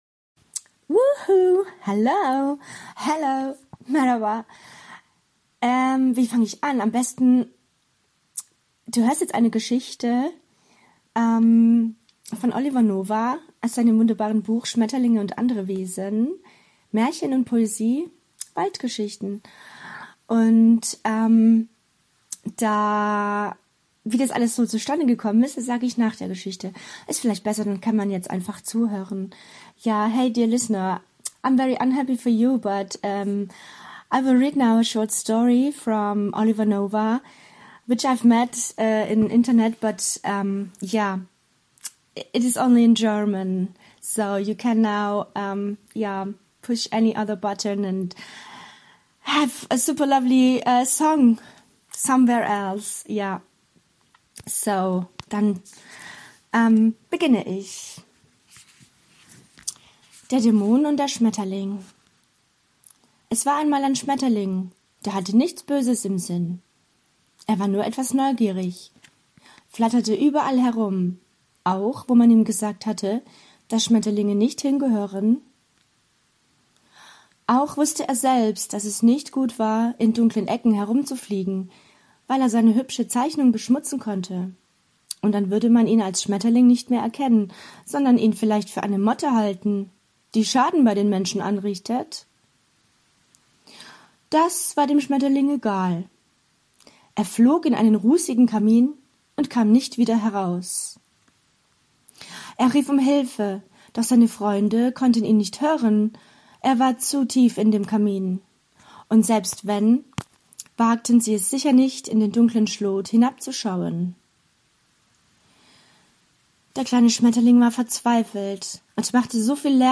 Der Punkcast ist ein Live-Mitschnitt & die Weiterentwicklung von "Switch - Für immer Punk".